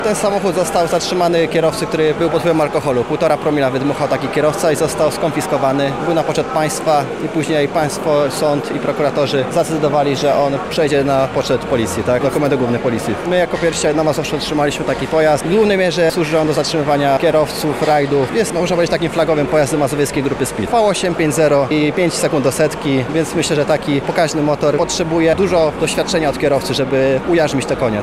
Policjant — mówi jeden z policjantów.